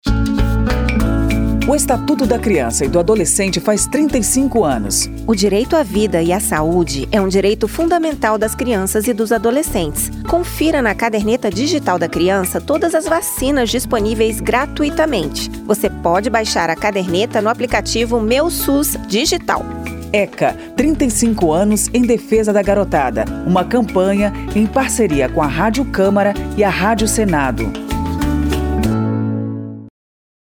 08-spot-eca-35-anos-parceiras.mp3